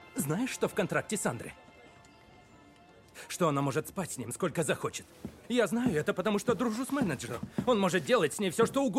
На этой странице собраны дикторские голоса, которые мы можем записать для вашего проекта.
Голос с уникальной глубиной и авторитетом